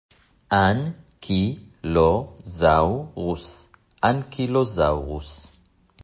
אנ-קי-לו-זאו-רוס